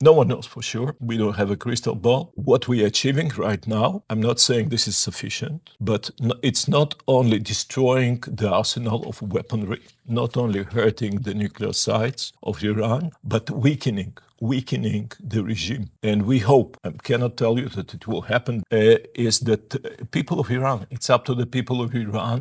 ZAGREB - Dok se cijeli svijet pita koliko će trajati rat na Bliskom istoku i kakve će globalne posljedice ostaviti, odgovore na ta pitanja potražili smo u Intervjuu tjedna Media servisa od izraelskog veleposlanika u Zagrebu Garyja Korena.